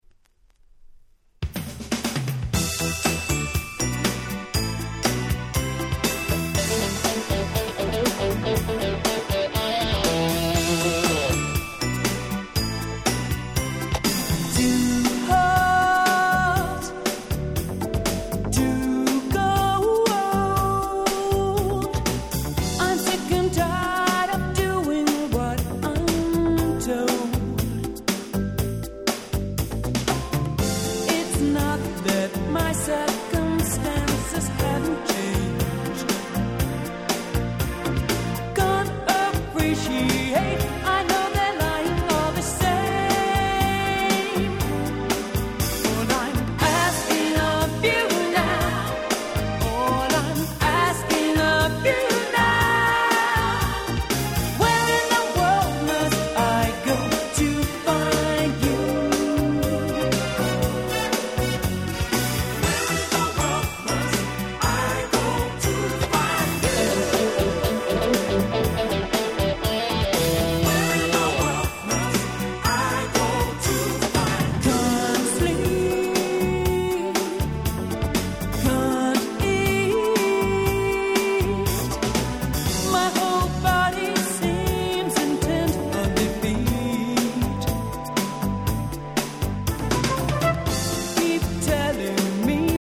89' Nice UK R&B LP !!